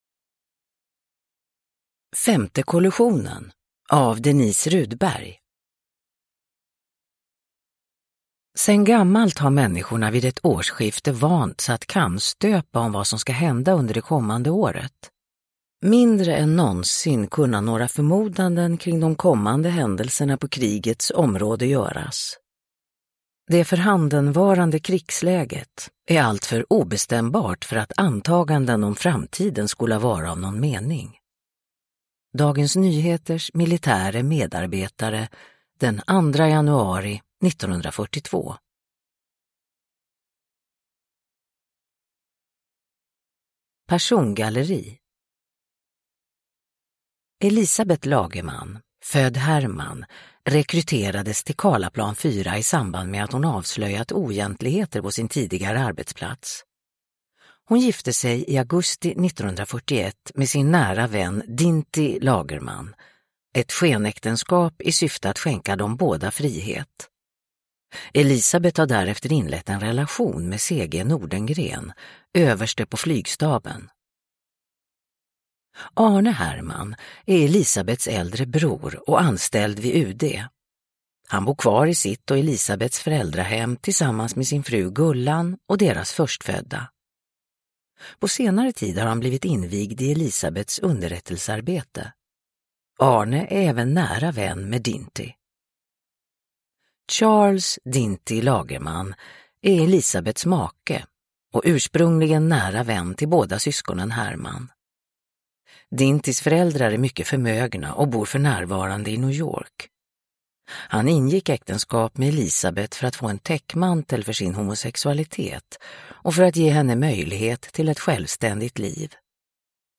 Femte kollusionen – Ljudbok
Uppläsare: Marie Richardson